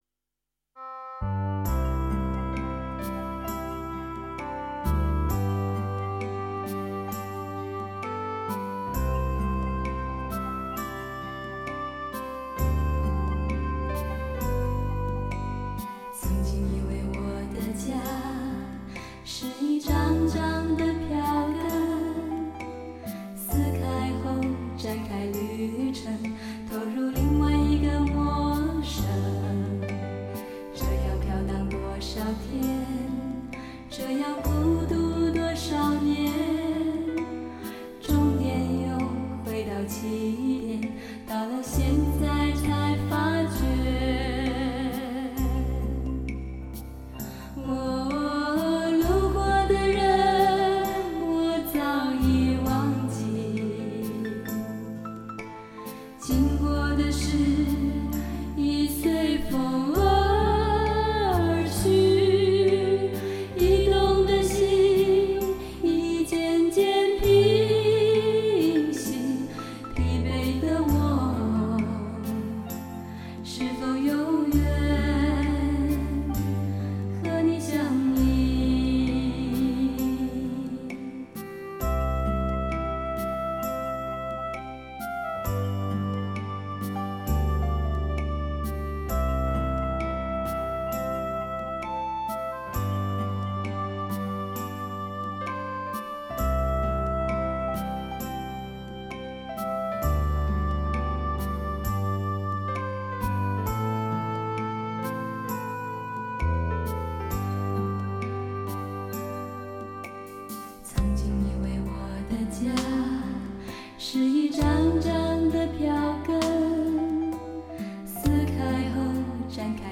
dts-CD试音碟，原始分轨，数码制作
耳熟能详的怀旧老情歌，多为男女声柔情对唱、重唱及女声独唱。